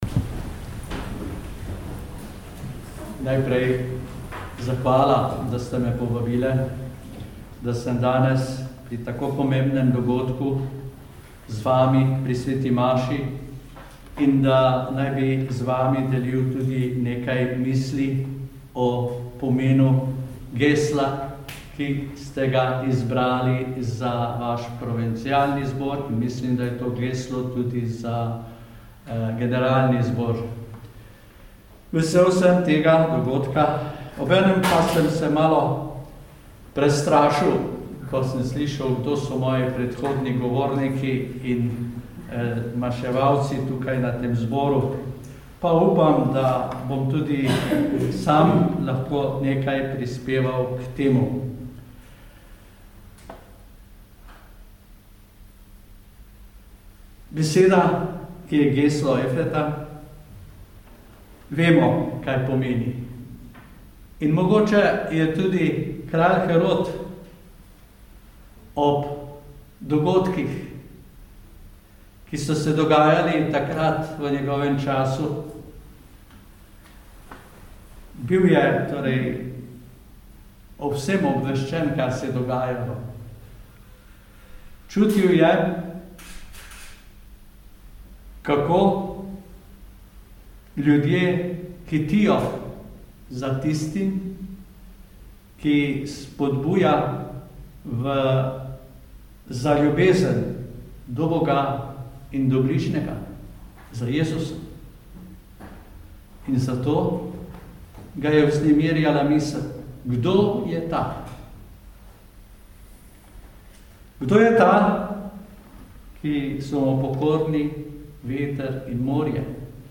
Pridiga